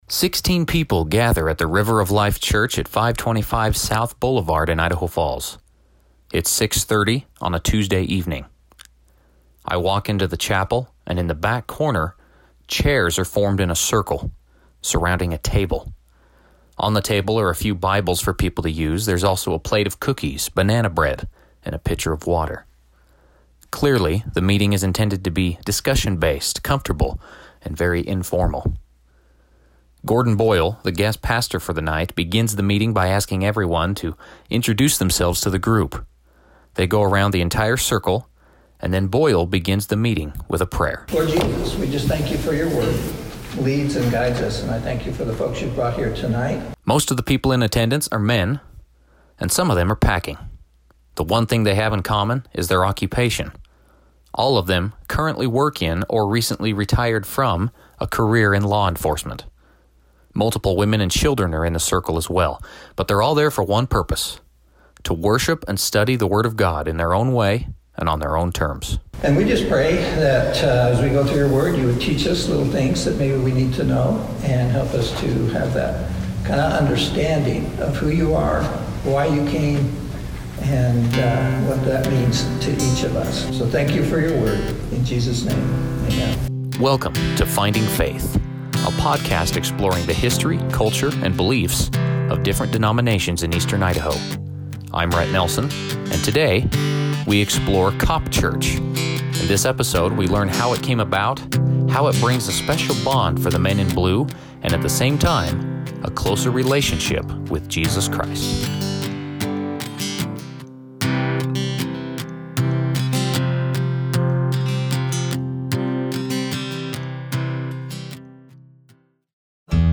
Over the last six months, we’ve attended many different church services and spoken with members of different faiths to understand who they are and what they believe. Each episode in the six-part series will focus on a specific denomination and explore the history, culture and beliefs of the religion.